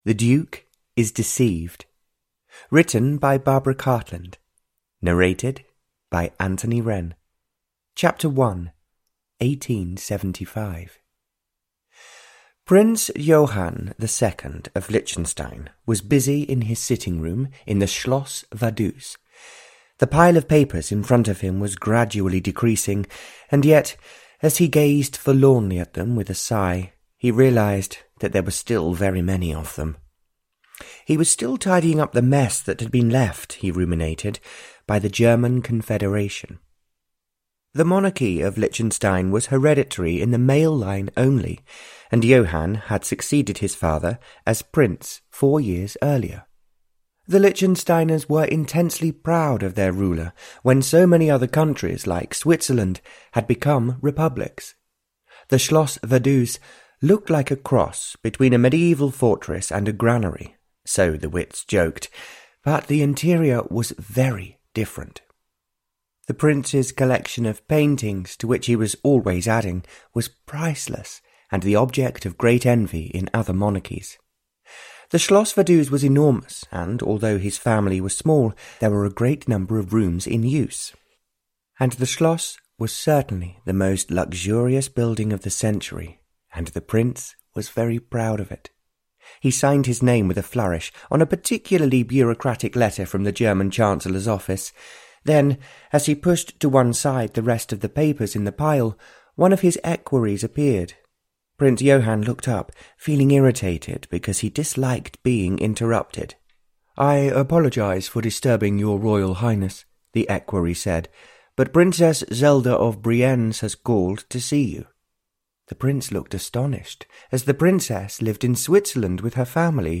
The Duke is Deceived (Barbara Cartland's Pink Collection 97) – Ljudbok – Laddas ner